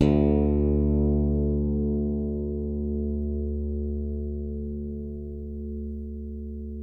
WARW.FING D2.wav